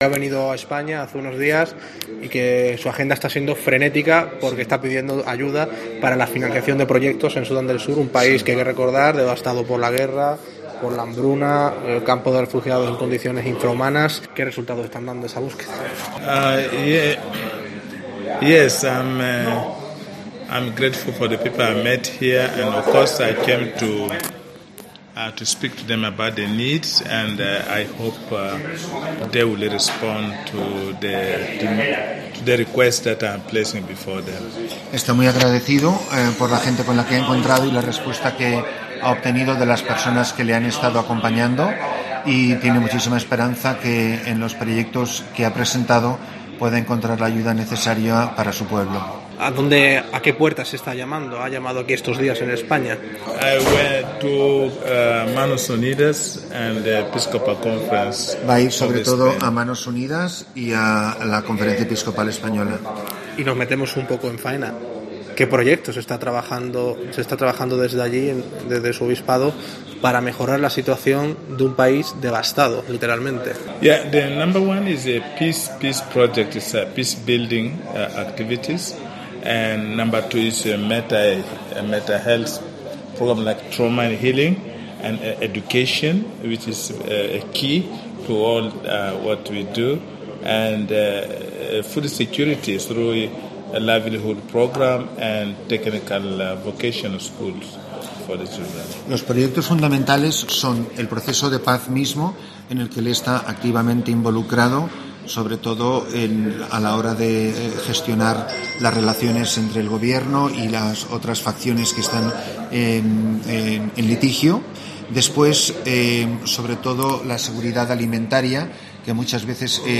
No habla castellano, por lo que ha estado acompañado en todo momento por el misionero anglo-español en Sudán del Sur,